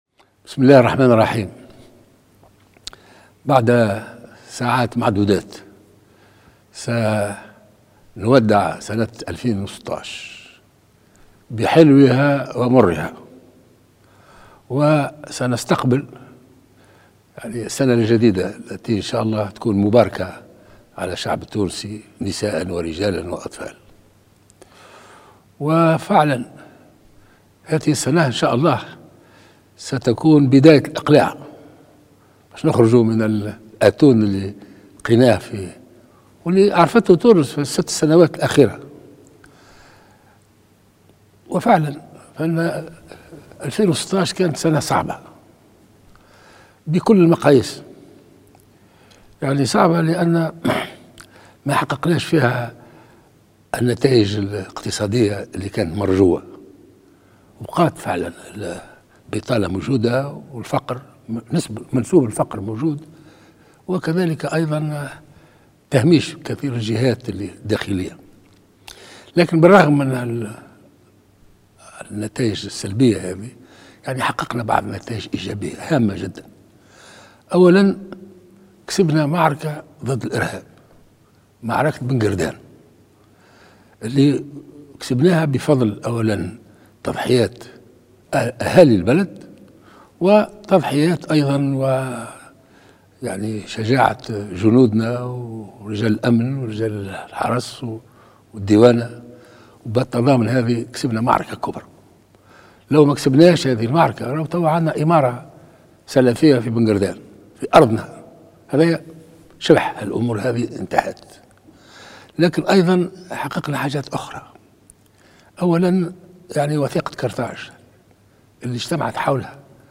قال رئيس الجمهورية الباجي قايد السبسي في كلمة مسجلة توجه من خلالها للشعب التونسي مساء اليوم السبت بمناسبة حلول السنة الجديدة، إن عام 2017 سيكون بداية الإقلاع.